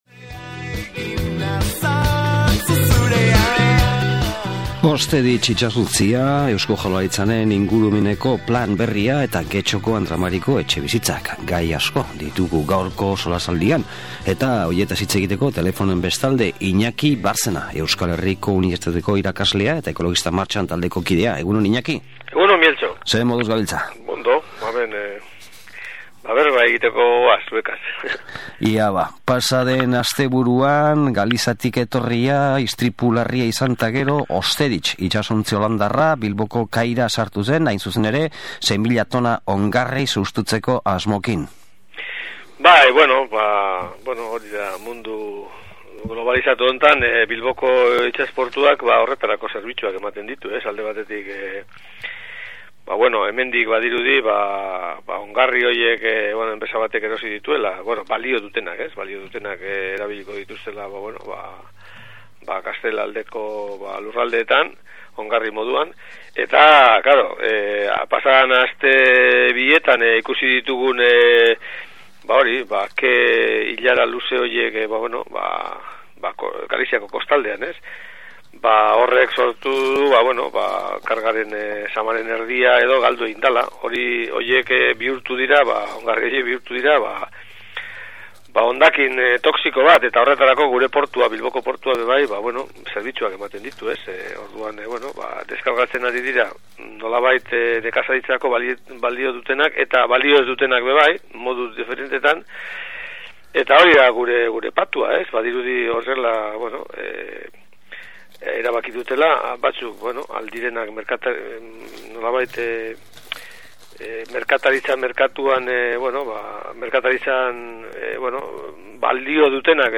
SOLASALDIA: E. Jaurlaritzaren ingurumeneko plan berria